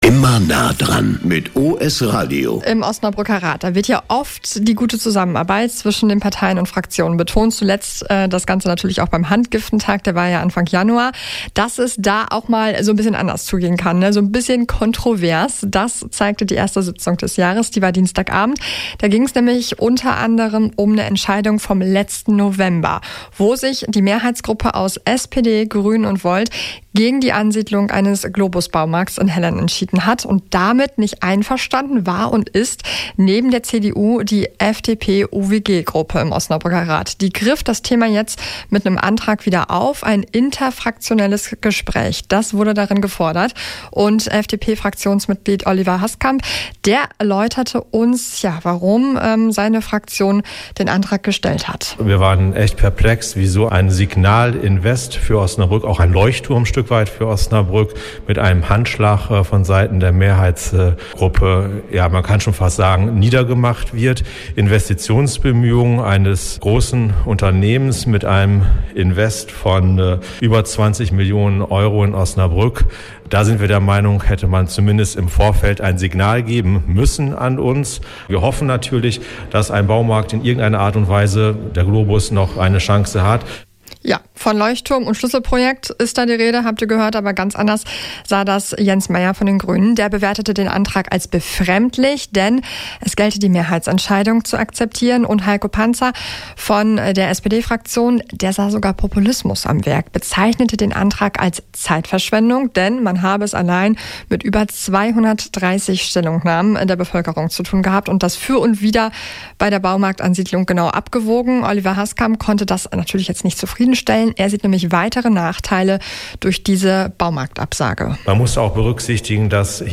Mehr dazu in folgendem Mitschnitt aus unserem Programm: